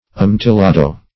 Search Result for " amontillado" : Wordnet 3.0 NOUN (1) 1. pale medium-dry sherry from Spain ; The Collaborative International Dictionary of English v.0.48: Amontillado \A*mon`til*la"do\, n. [Sp.]